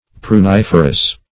Pruniferous \Pru*nif"er*ous\, a.